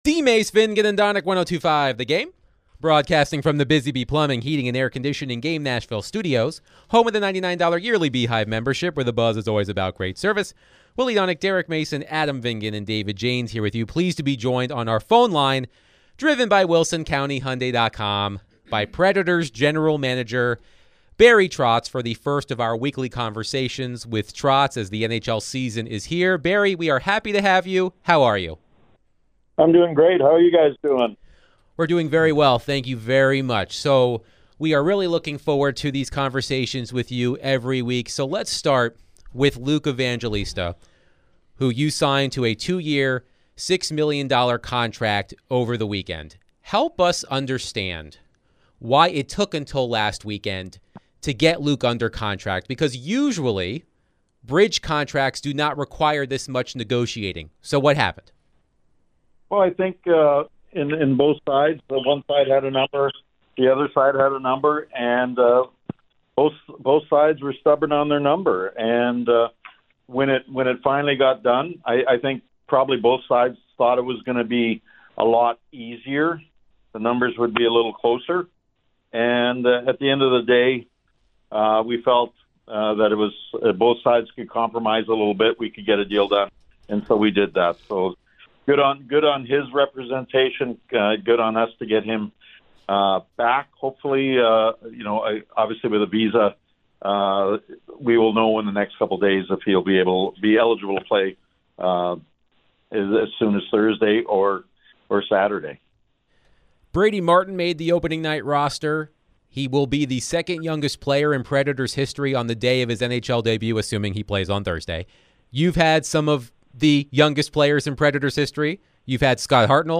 Nashville Predators General Manager Barry Trotz joins DVD to discuss all things Nashville Predators, Brady Martin, Luke Evangelista signing, Juuse Saros struggles, Brunette's hot seat, and more